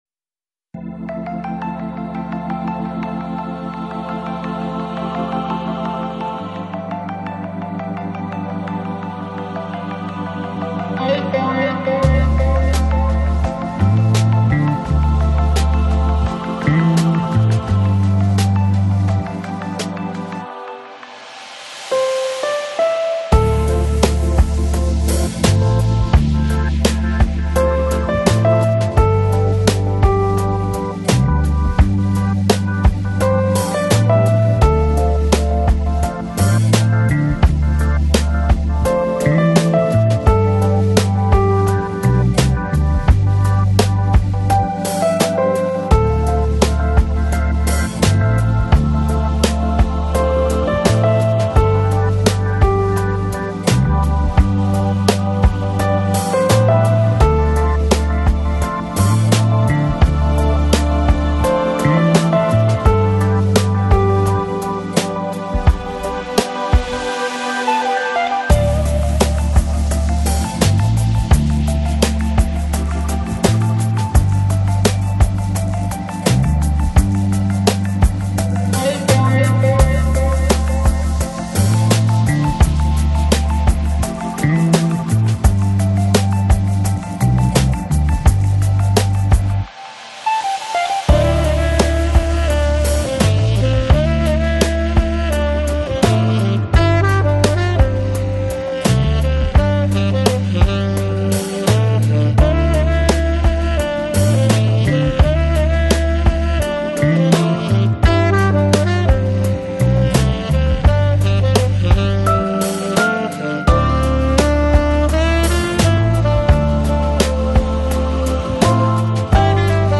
Жанр: Lounge, Chill Out, Lo-Fi, Downtempo